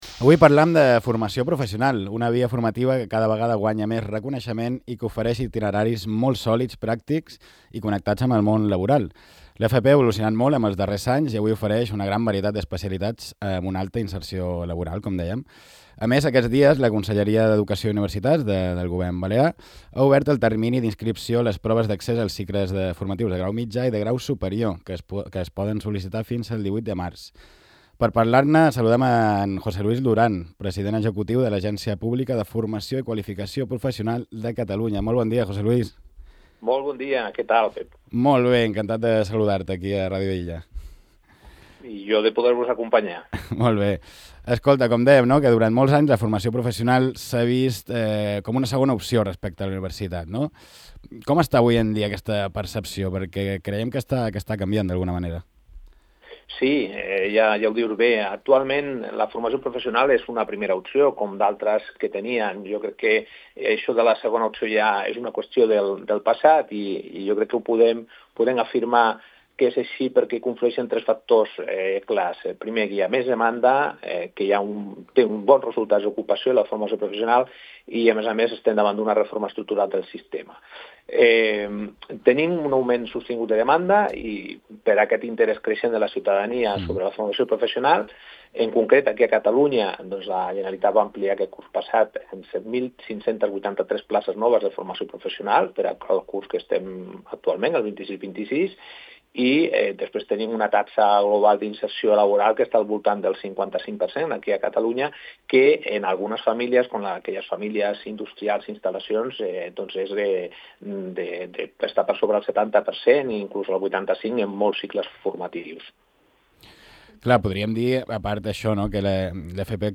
En una entrevista a Ràdio Illa, el president executiu de l’Agència Pública de Formació i Qualificació Professionals de Catalunya, José Luis Durán, ha destacat que la percepció de l’FP ha canviat notablement en els darrers anys.